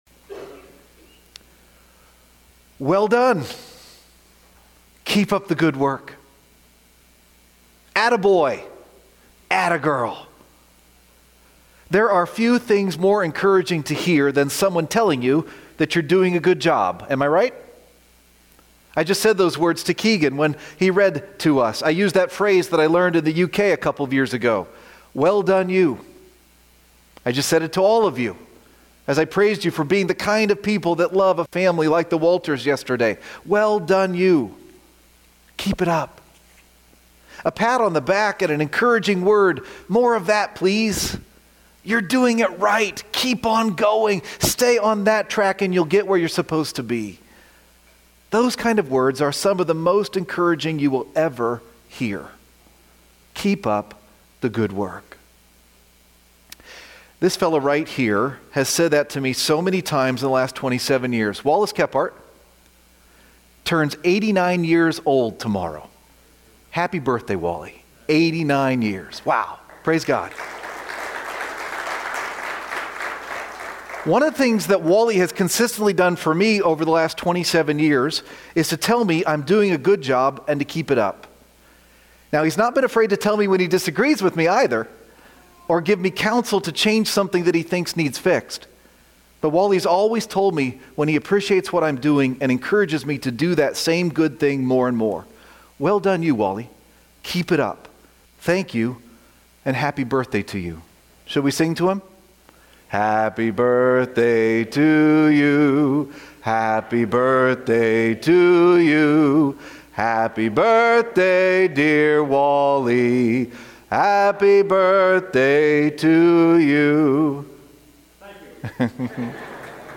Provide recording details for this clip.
Do This More and More :: June 1, 2025 - Lanse Free Church :: Lanse, PA